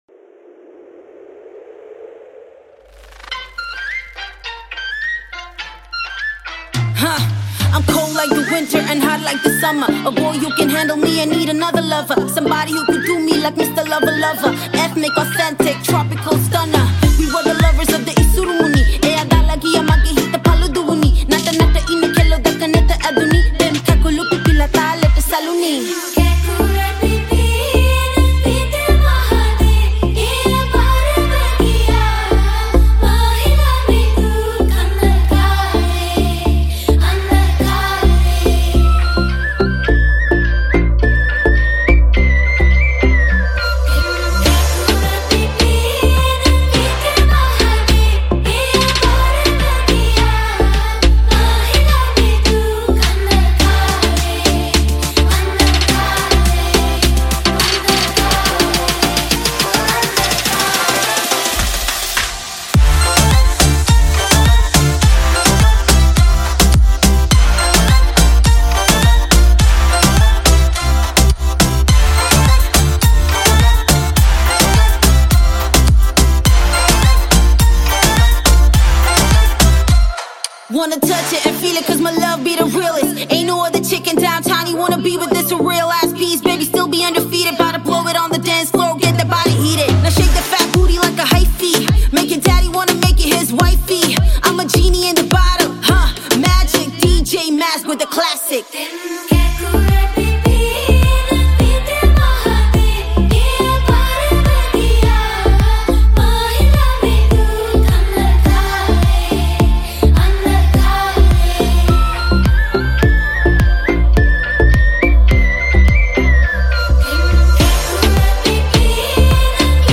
Rap Verse
Vocals